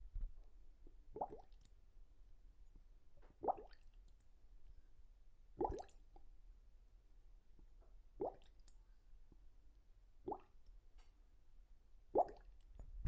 熔岩声音 " 熔岩泡沫
描述：岩浆